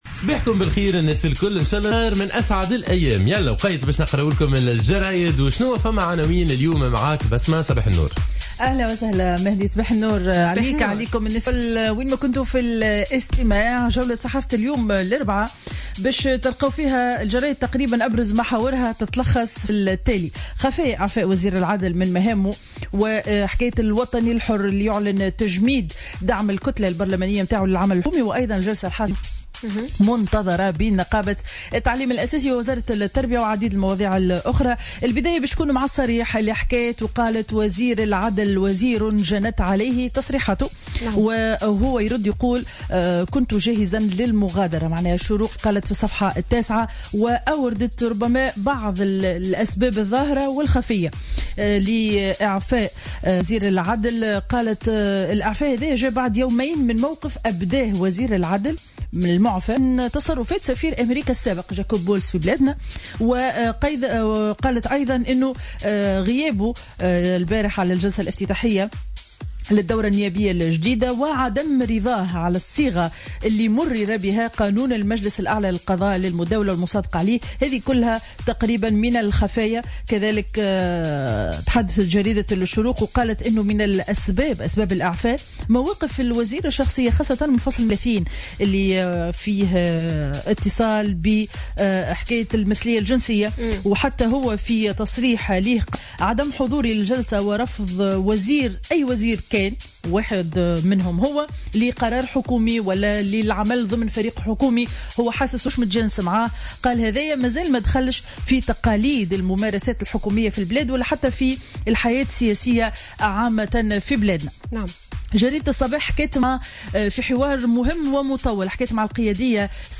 Revue de presse du mercredi 21 octobre 2015